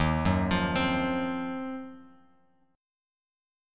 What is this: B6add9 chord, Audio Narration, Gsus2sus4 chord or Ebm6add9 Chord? Ebm6add9 Chord